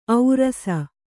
♪ aurasa